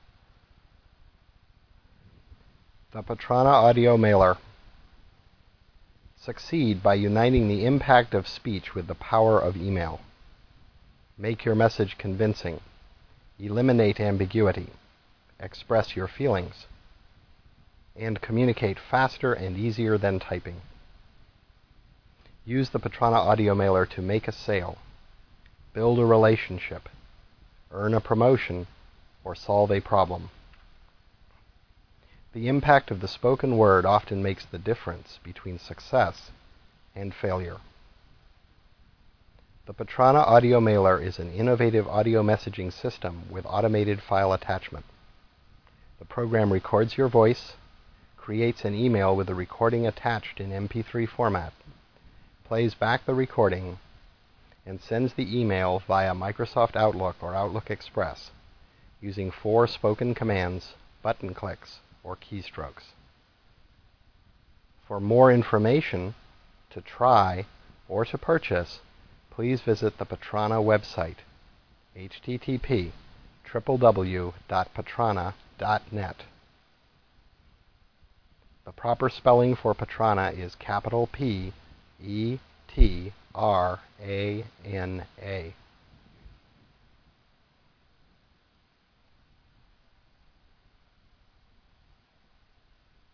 This is an audio announcement (0 Hours, 01 Minutes, 36 Seconds long) in MP3 audio format.